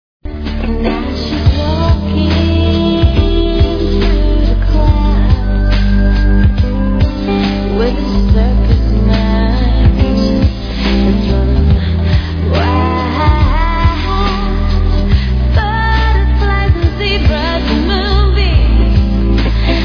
(Unmixed version)